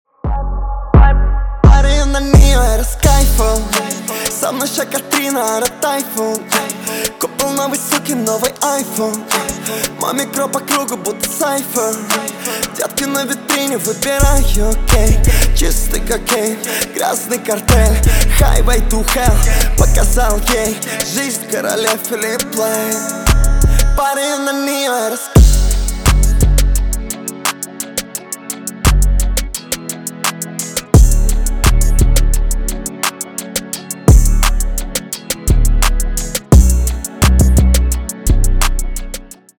Рэп и Хип Хоп # с басами